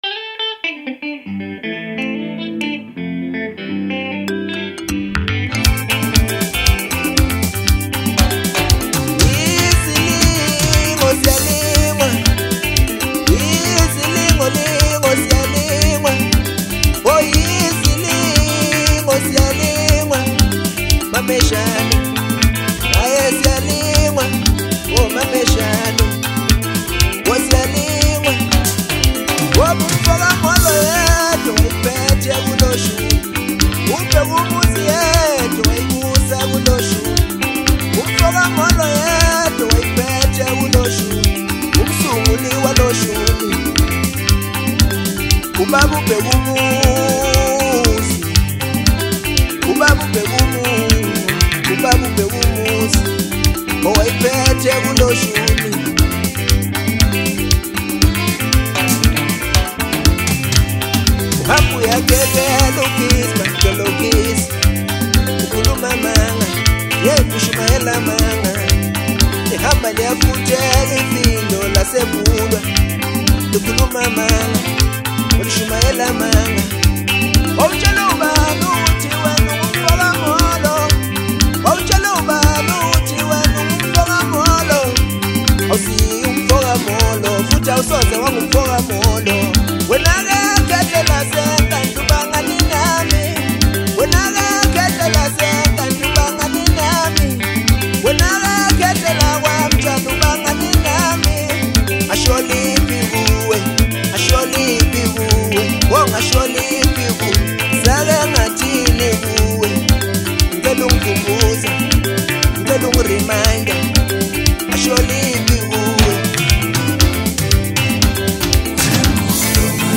December 29, 2025 admin Maskandi 0